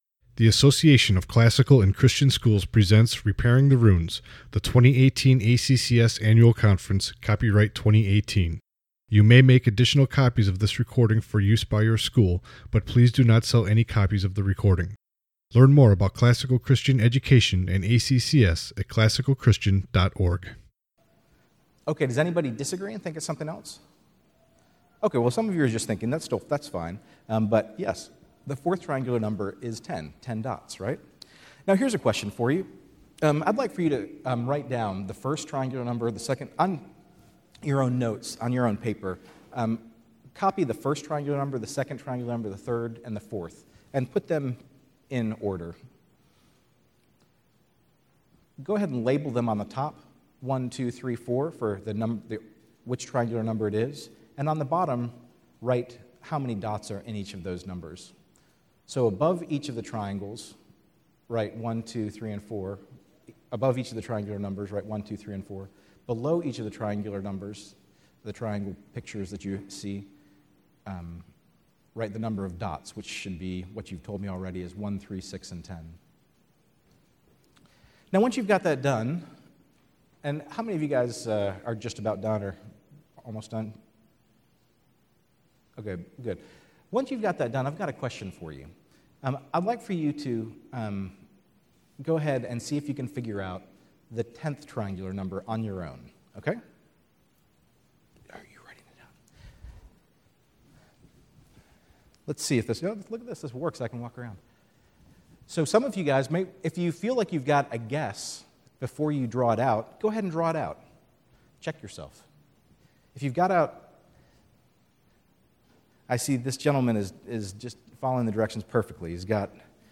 2018 Workshop Talk | 54:33 | All Grade Levels, Math